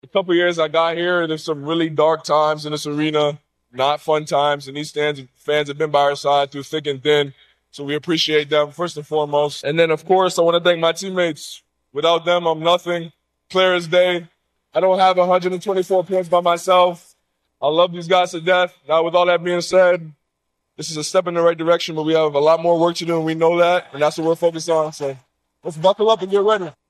After he got honor he thanked Oklahoma City and his teammates.